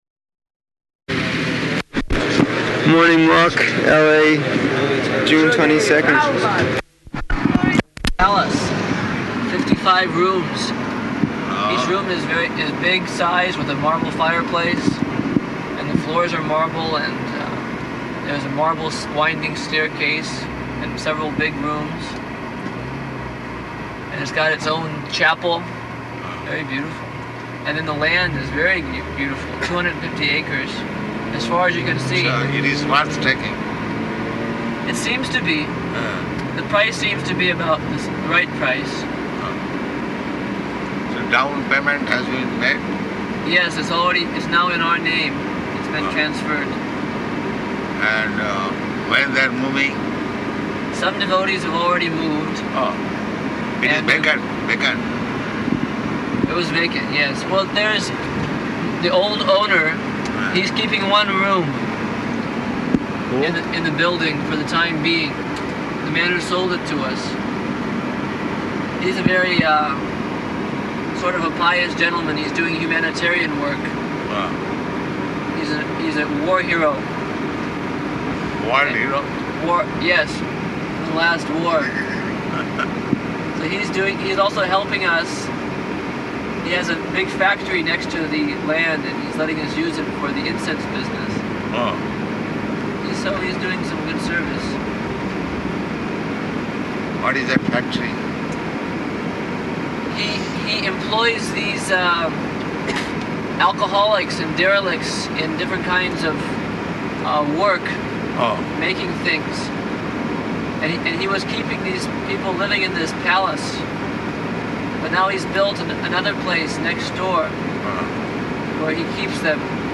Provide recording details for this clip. June 22nd 1975 Location: Los Angeles Audio file